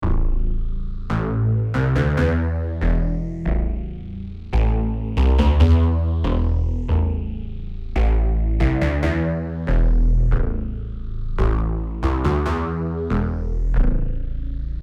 le son sans fx : il y a quand même le phaseur du modular
modcan1_dry.mp3